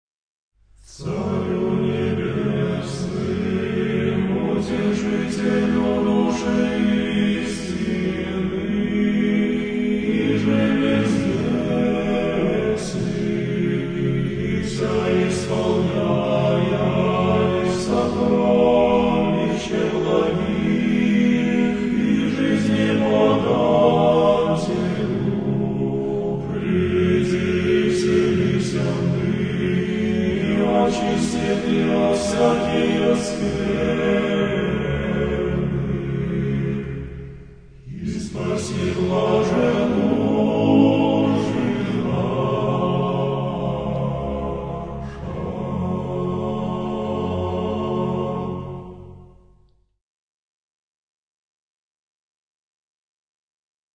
Песнопения праздника Троицы
Архиерейский хор Нижегородской епархии
Arhiereyskiy-hor-Nizhegorodskoy-Eparhii-Caryu-Nebesnyymuzofon.com_.mp3